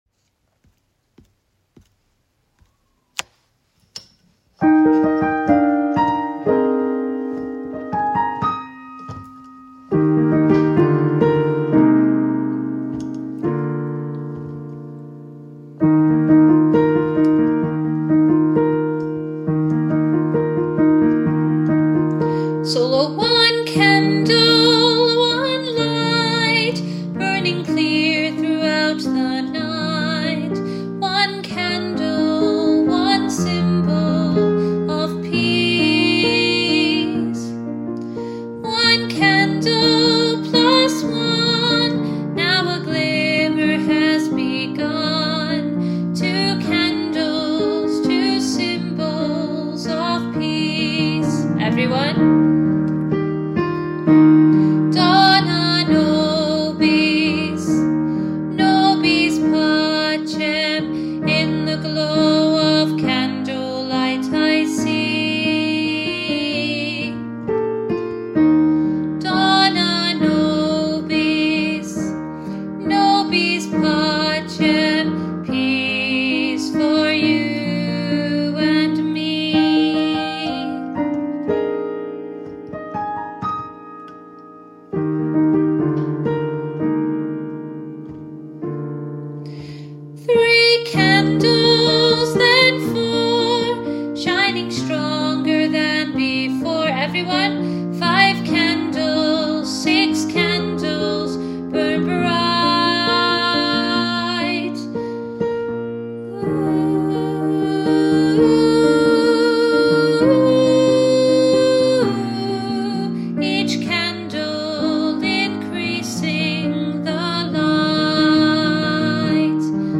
one-candle-alto-part-two.mp3